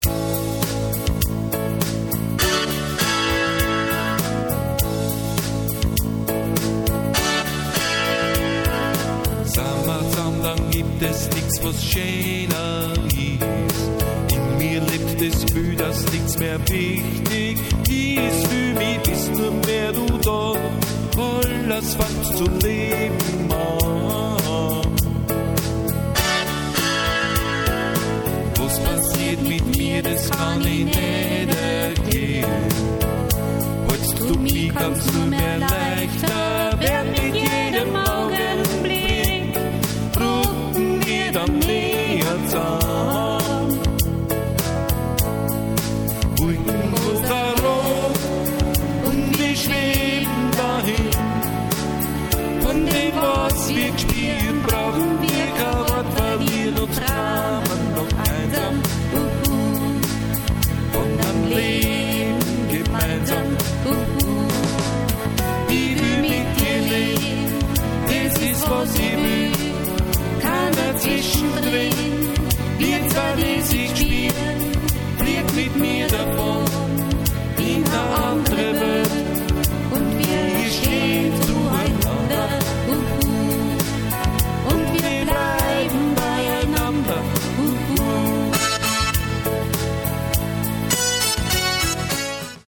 Demo CDs und Demo Songs
Sie wurden alle mit der AW 4416 Workstation aufgenommen und fertig gemischt.